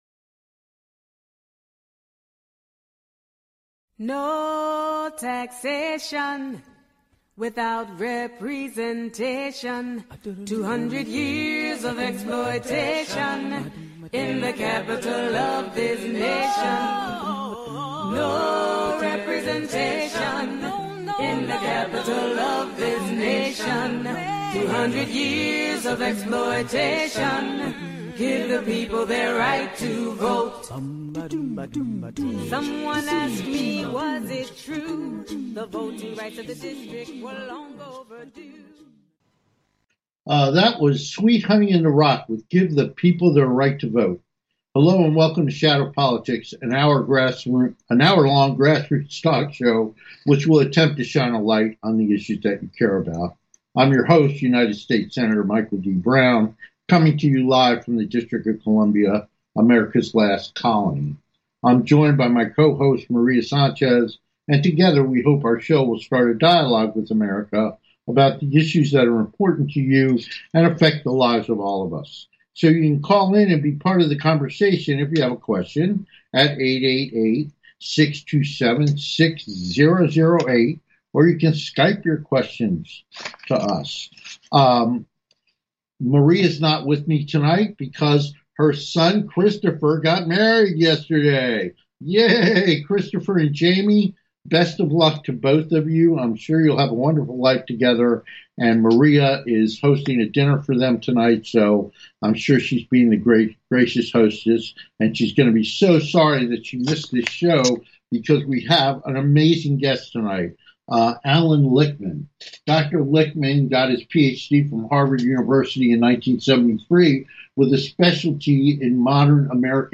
Talk Show Episode, Audio Podcast, Shadow Politics and Guest, Allan Lichtman on , show guests , about Allan Lichtman, categorized as News,Politics & Government,Society and Culture,Theory & Conspiracy
Guest, Allan Lichtman